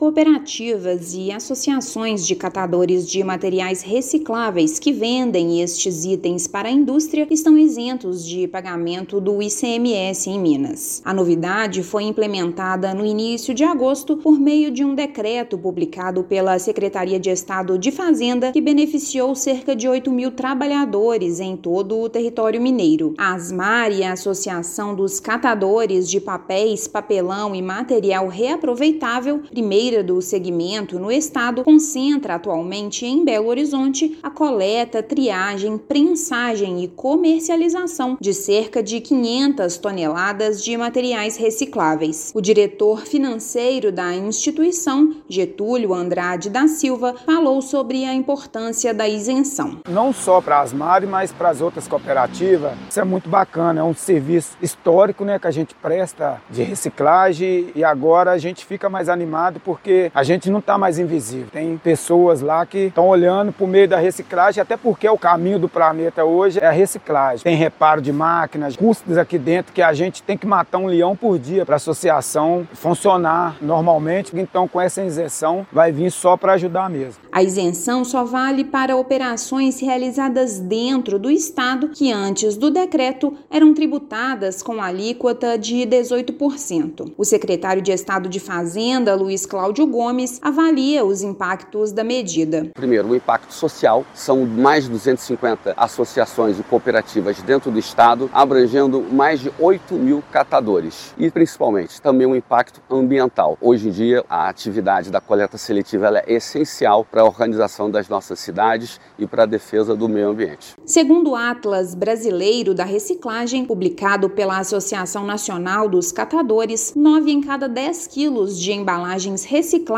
Publicada pela Secretaria de Estado de Fazenda, norma retira imposto na venda de sucatas por cooperativas e associações, com objetivo de fomentar setor. Ouça matéria de rádio.